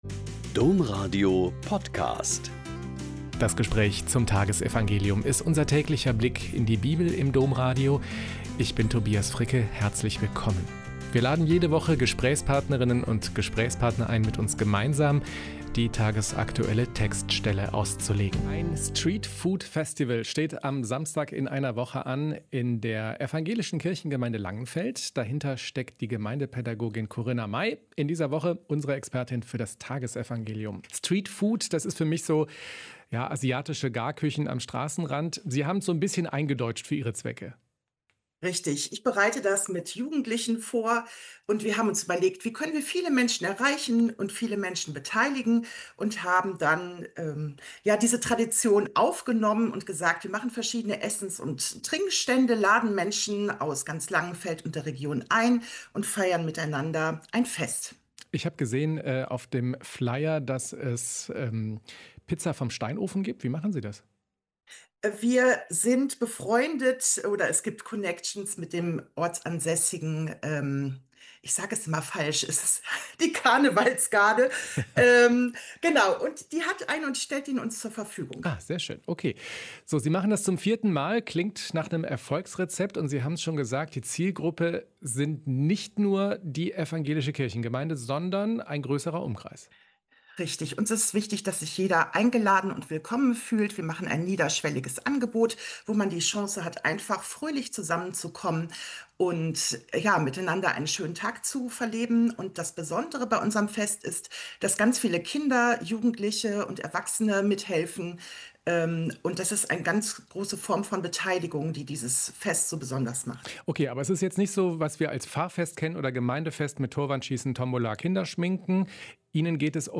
Mt 23,27-32 - Gespräch